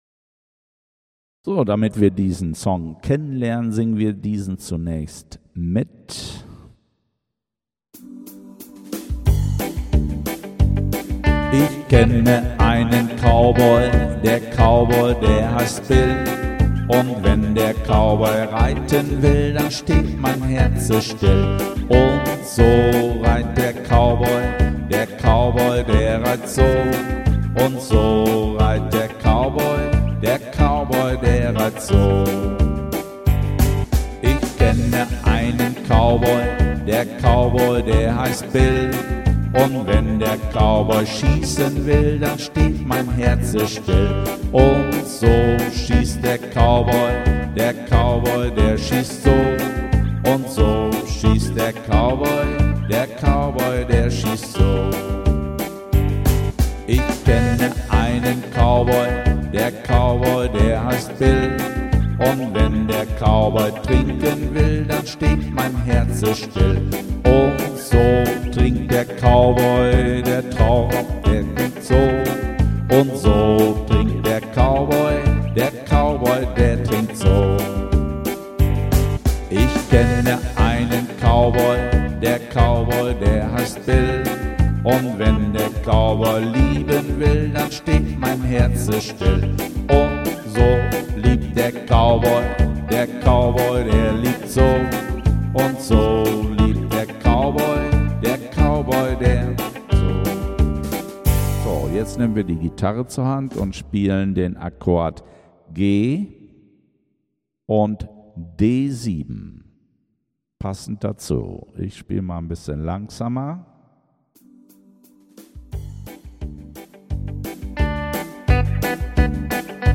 Gitarren Ag Wir spielen in G Dur